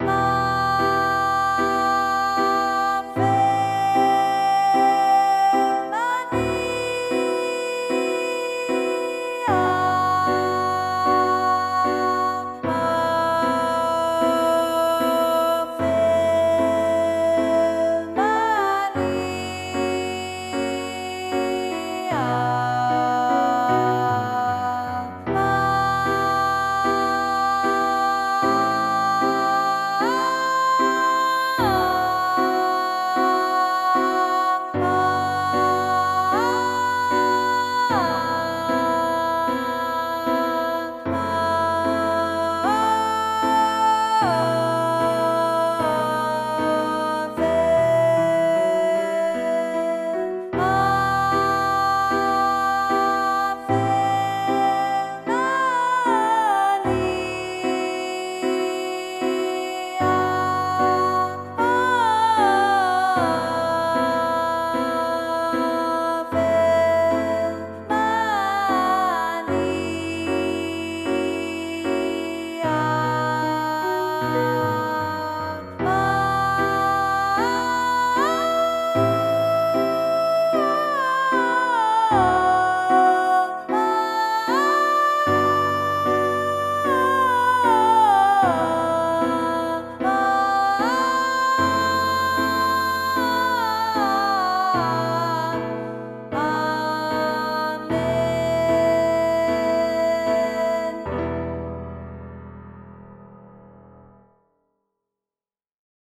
音取り音源